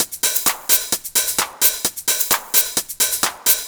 130_HH+shaker_1.wav